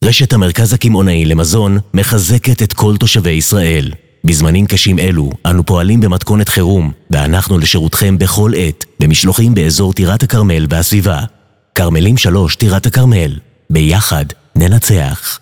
רשת המרכז הקמעונאי – ללא מוזיקה
רשת-המרכז-הקמעונאי-ללא-מוזיקה.mp3